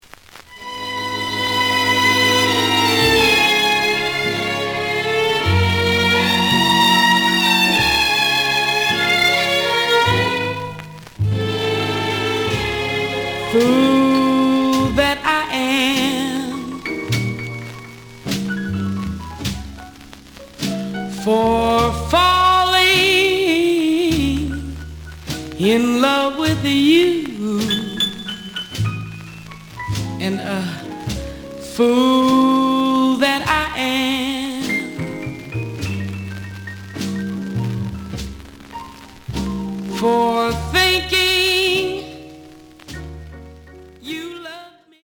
The audio sample is recorded from the actual item.
●Genre: Rhythm And Blues / Rock 'n' Roll
Some noise on A side.)